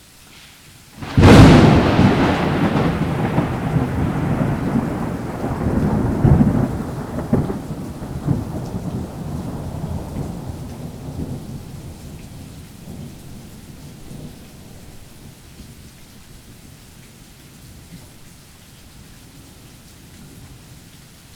bourka.wav